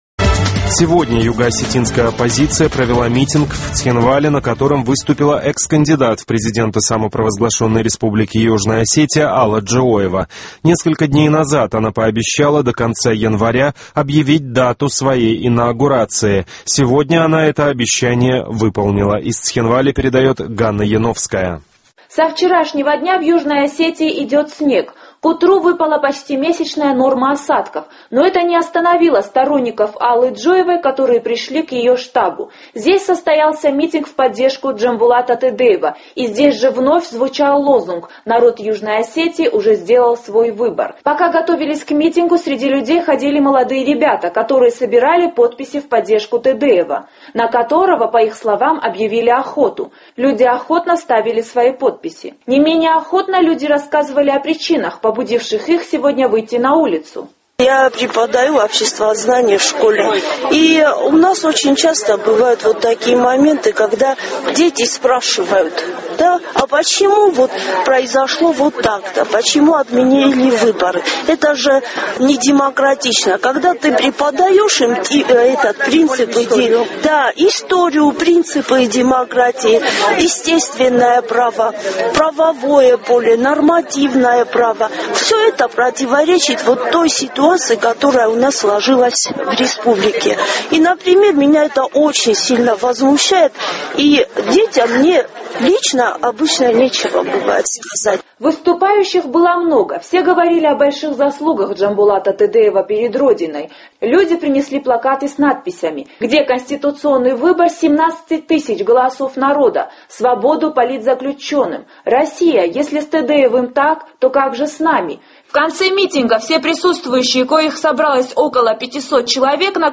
Югоосетинская оппозиция провела митинг в Цхинвале, на котором выступила экс-кандидат в президенты Алла Джиоева.